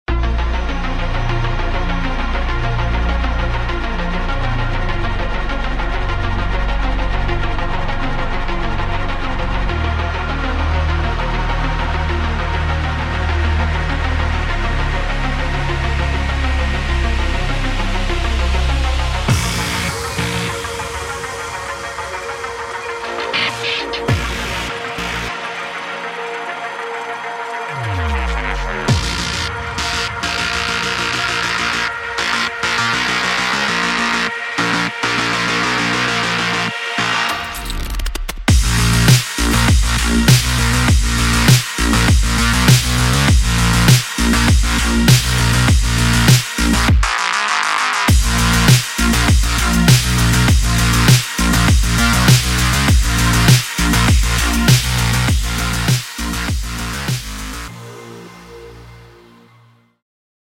Synthwave